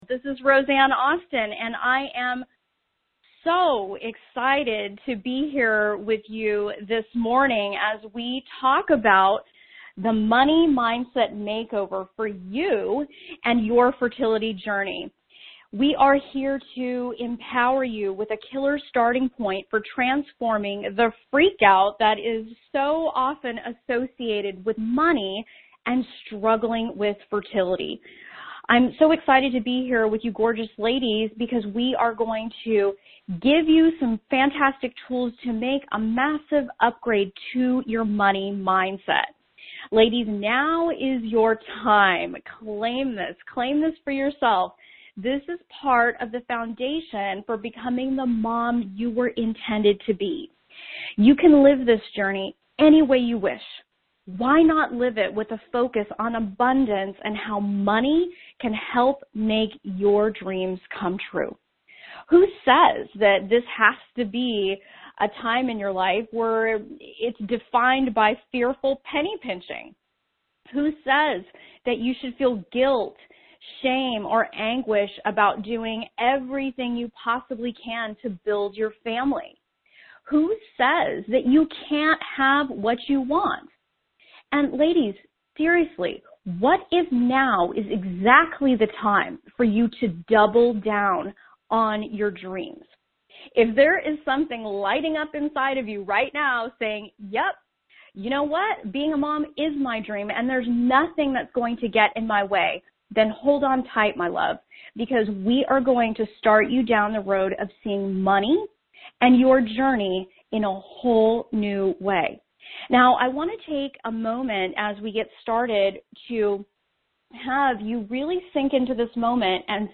Tune in into this month's teleseminar.